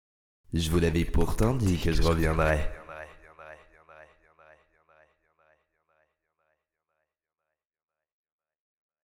voix-off.mp3